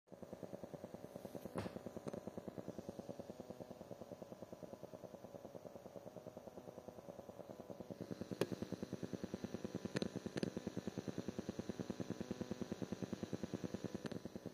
Problem z dźwiękiem (ESP32 + TPA3110)
Niestety zaraz po podłączeniu były słyszalne szumy w głośniku.
Próbowałem połączenia głośnika z kondensatorem 10uF ale nie usłyszałem znaczącej różnicy.